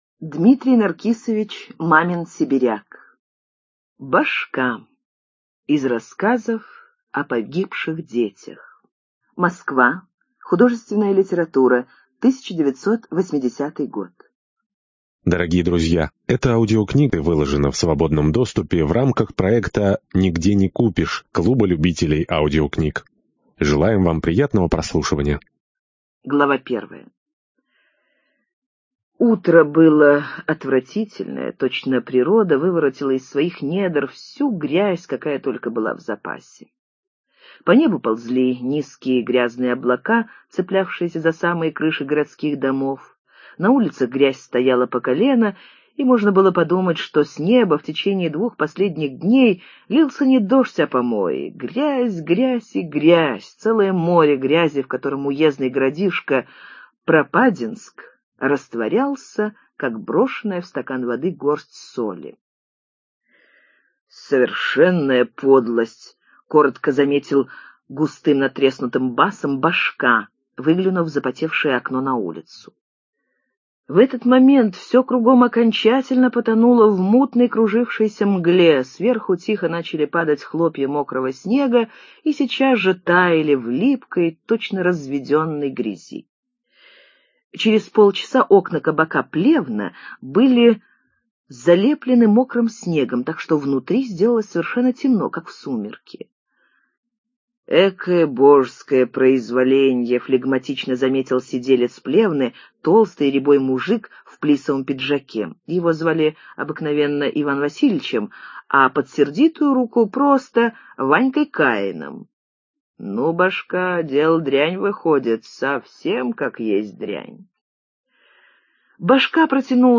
Башка — слушать аудиосказку Дмитрий Мамин-Сибиряк бесплатно онлайн